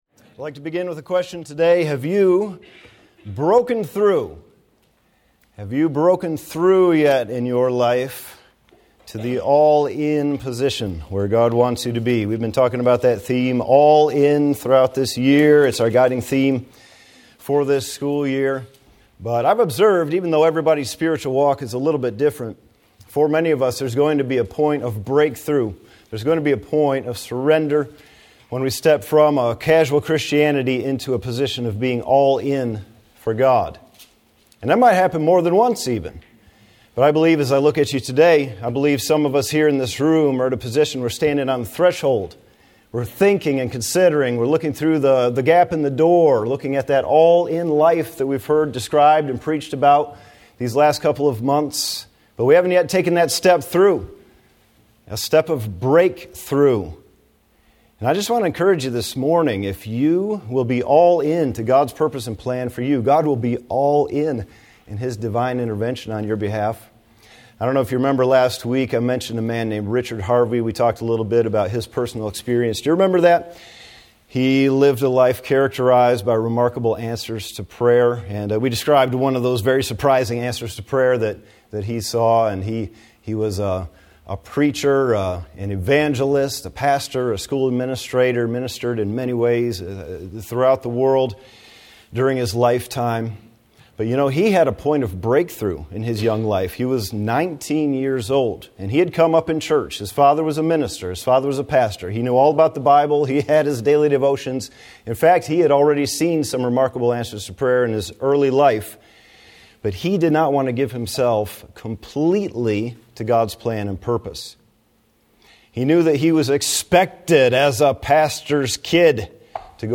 BCM Chapel